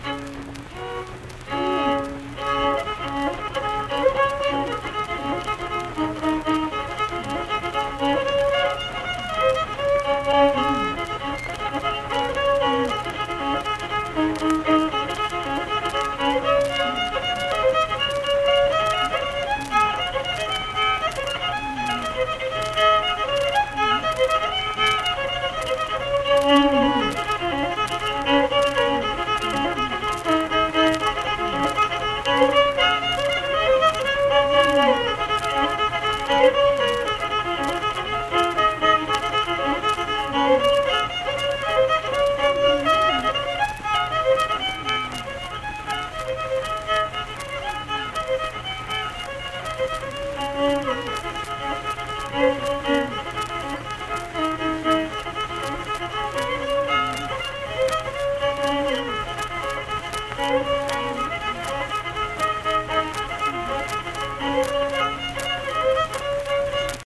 Unaccompanied fiddle music performance
Instrumental Music
Fiddle
Mannington (W. Va.) , Marion County (W. Va.)